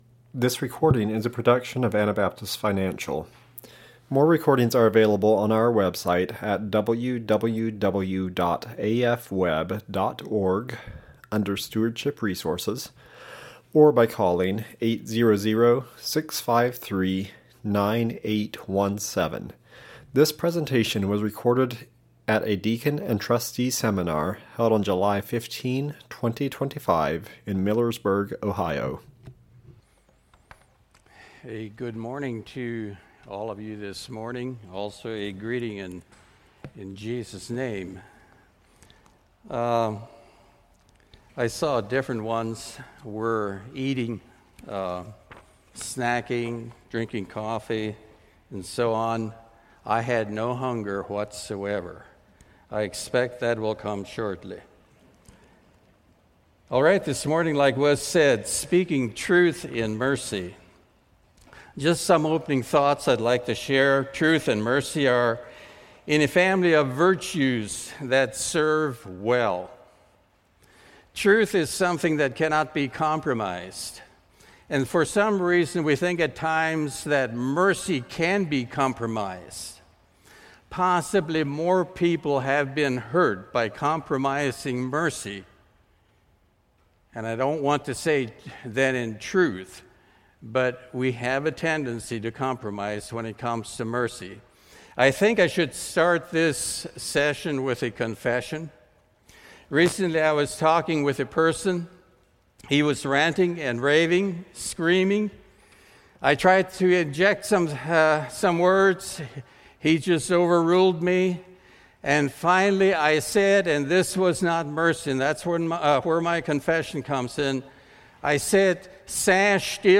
Ohio Deacons Seminar 2025